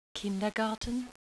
Read each word to yourself in the way that a German would pronounce it and then click on the sound icon next to each word to listen to it being spoken a German native speaker.